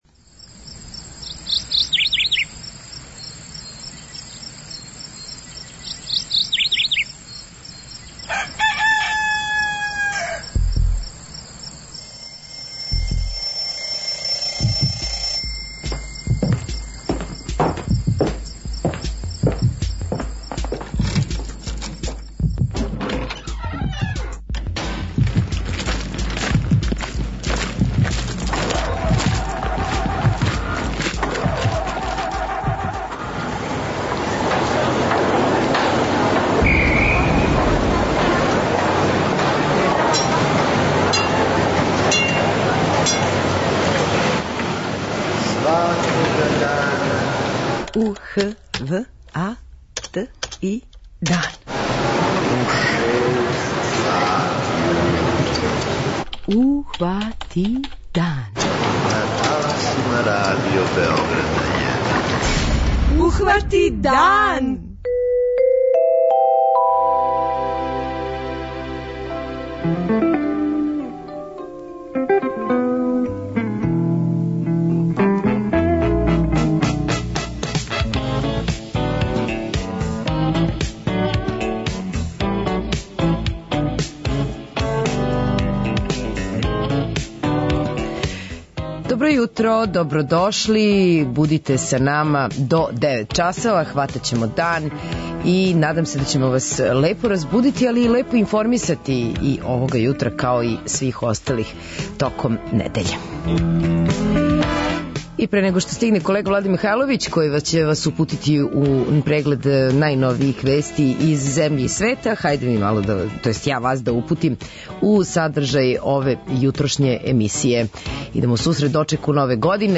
У програм ћемо укључити дописнике из Чачка, Врања, Лесковца, Пожаревца и Зрењанина да чујемо како теку припреме за највеселију ноћ.